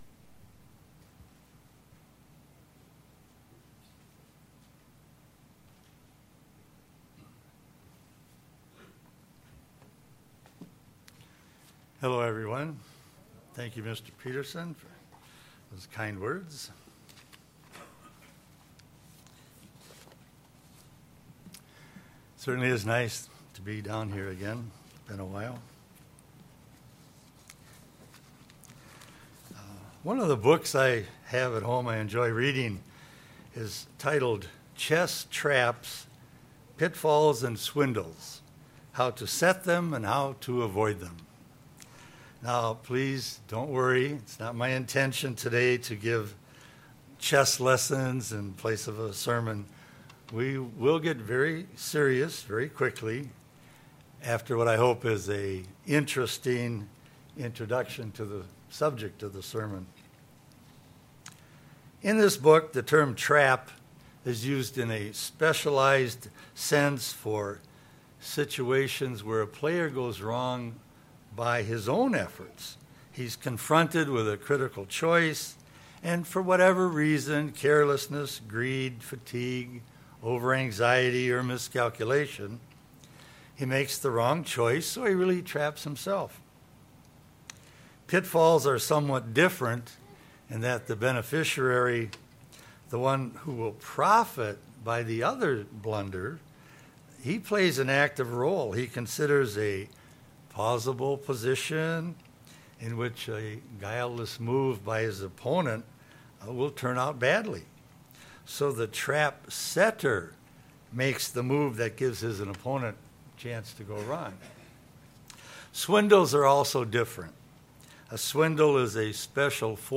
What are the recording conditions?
Given in Southern Minnesota